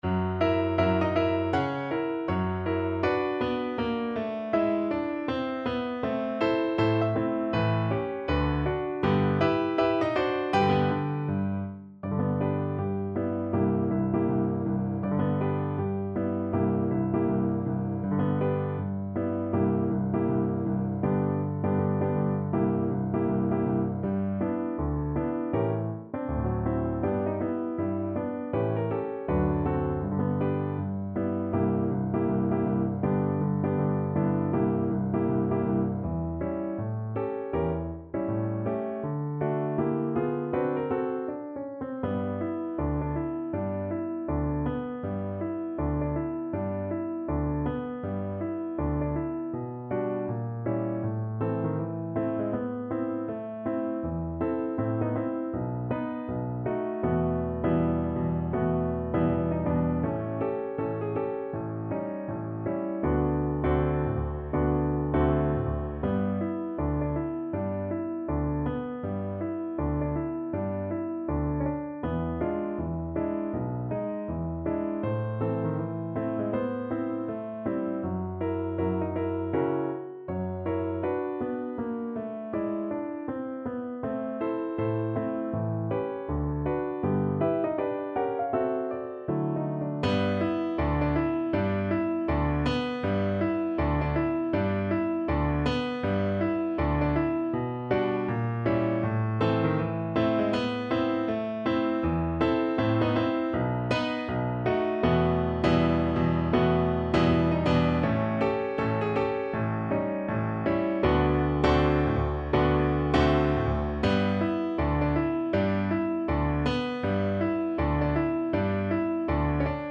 ~ = 160 Moderato
Jazz (View more Jazz Flute Music)